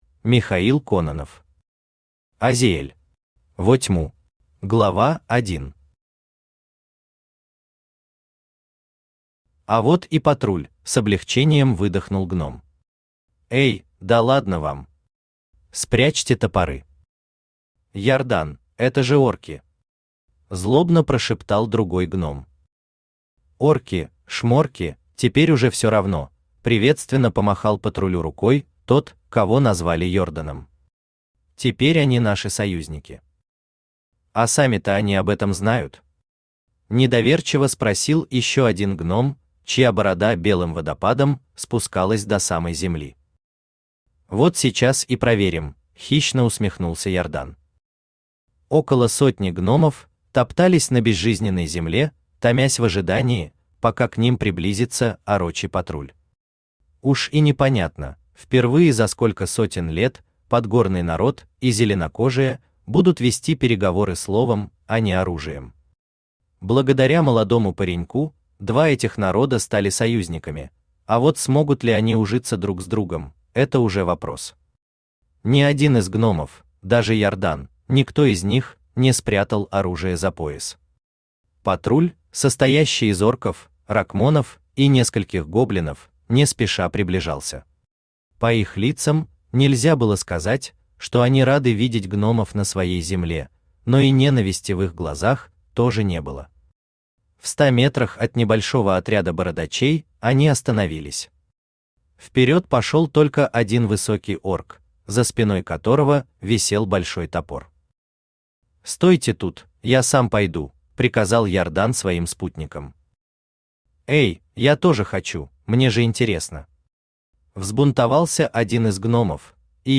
Аудиокнига Азиэль. Во Тьму | Библиотека аудиокниг
Во Тьму Автор Михаил Кононов Читает аудиокнигу Авточтец ЛитРес.